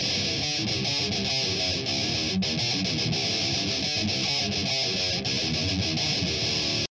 牧师金属吉他循环 " RE V LOOP S META L GUITA R 1
描述：rythum吉他循环播放heave groove循环播放
标签： 凹槽 吉他 金属 捶打
声道立体声